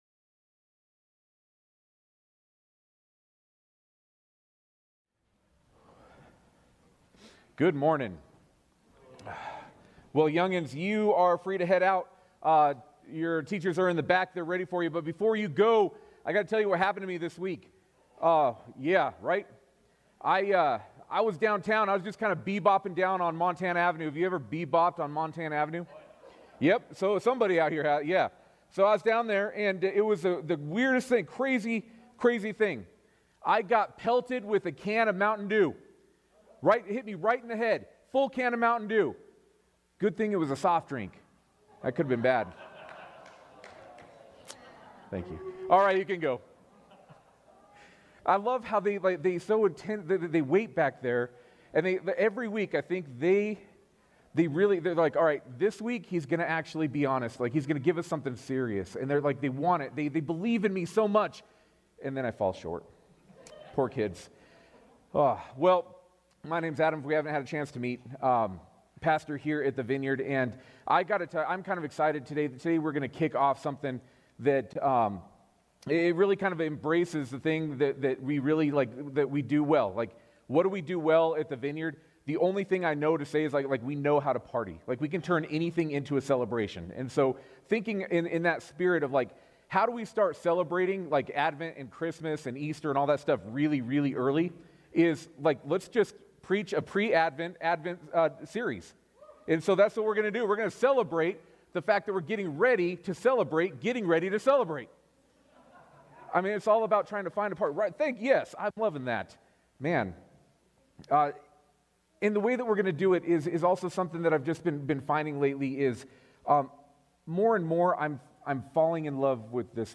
Sermons | Billings Vineyard Church